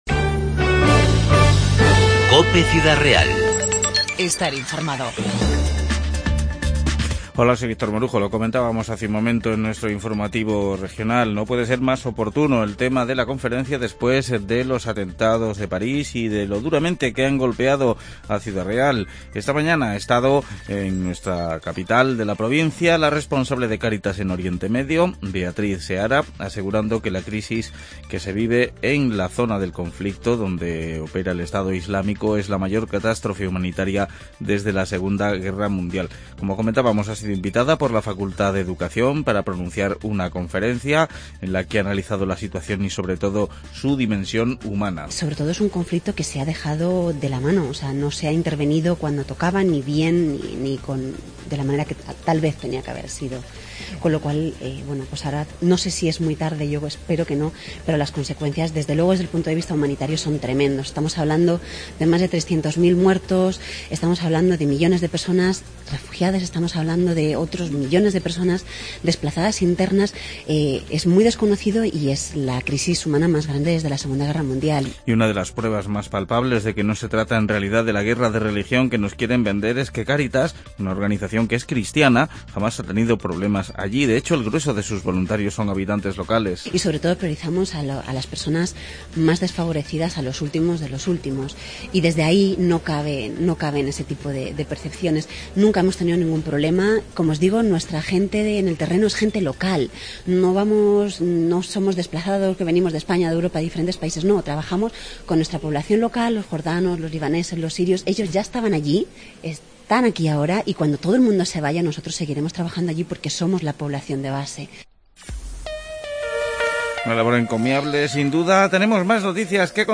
INFORMATIVO 17-11-15